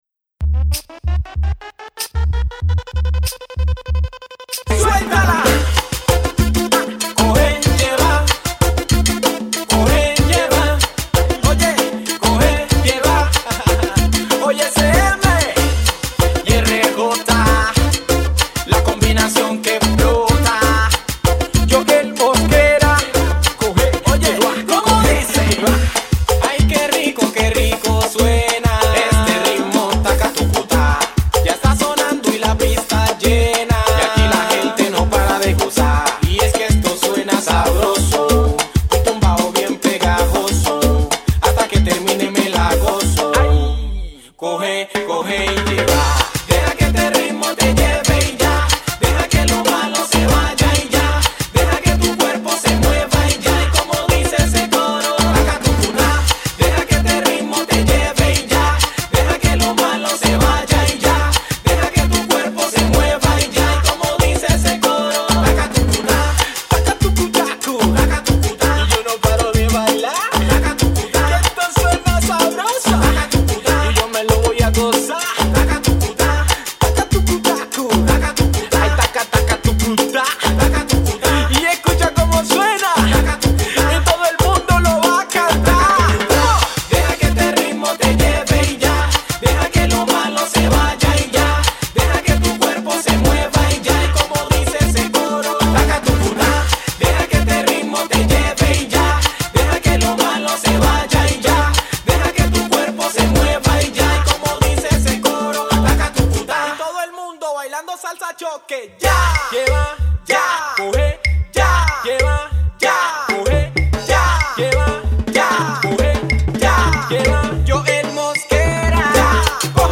Salsa choque